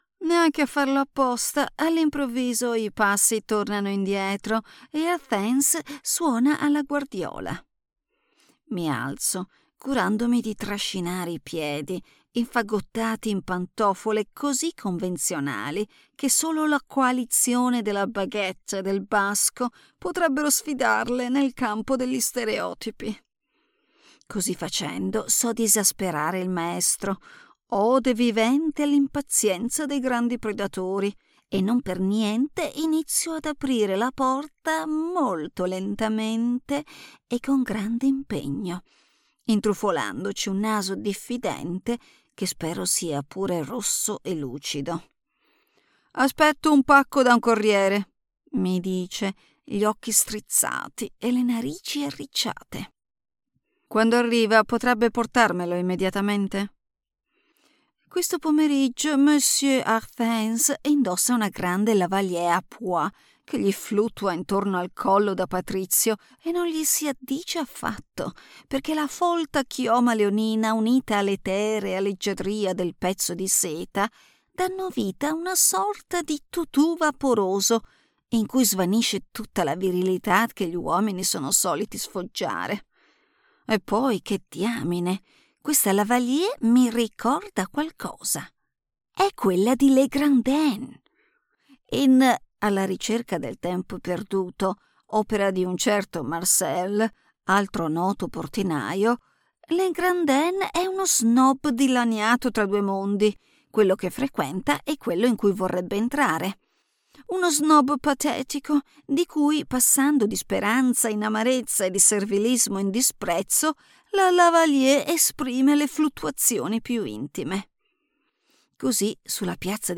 Audio Book Showreel
Female
Italian
English with International Accent
Bright
Confident
Smooth
Soft